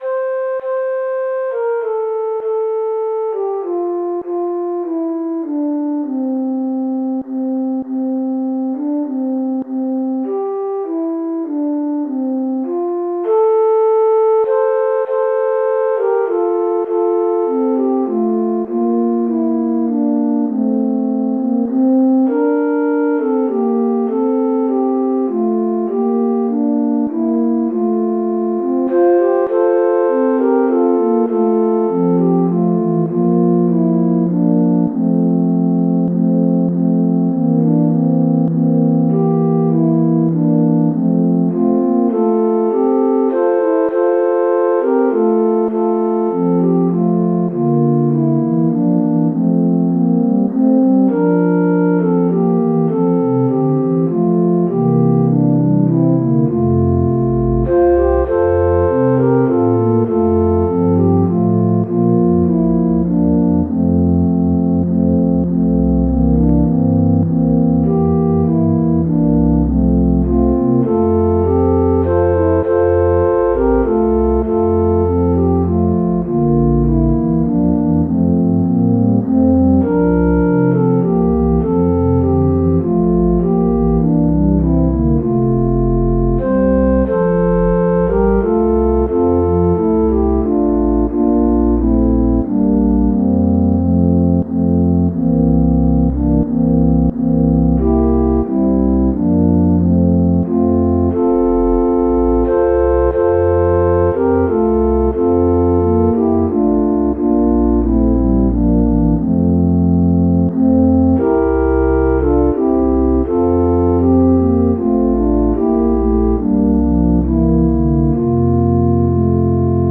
christmas
MIDI Music File